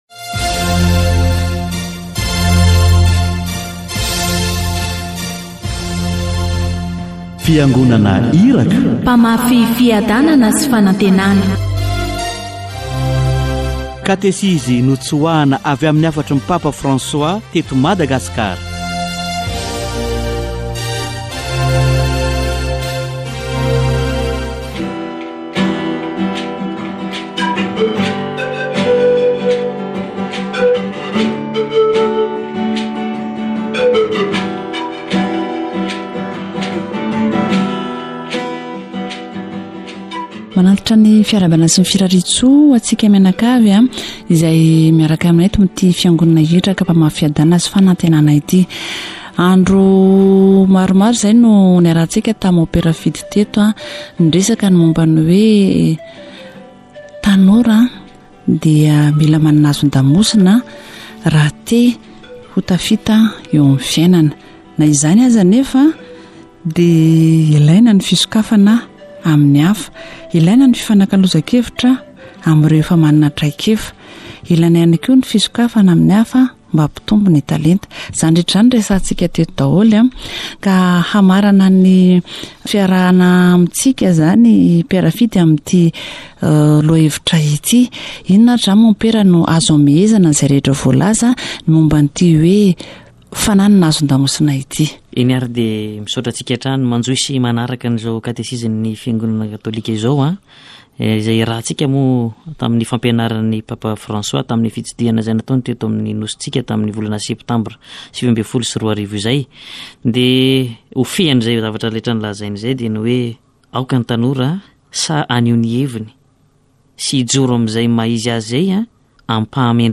Catechesis on personality